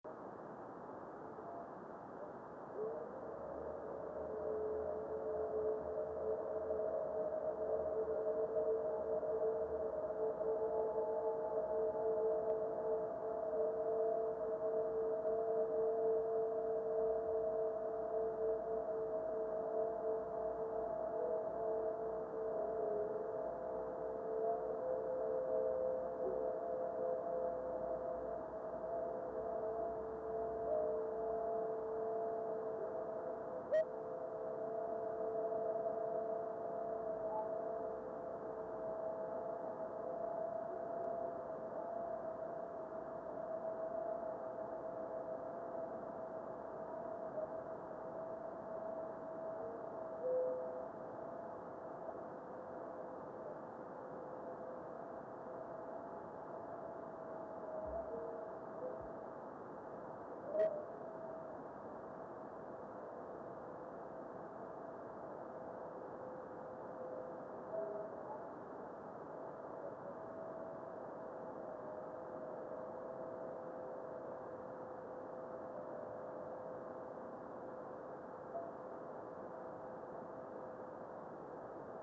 video and stereo sound:
Small meteor with long reflection.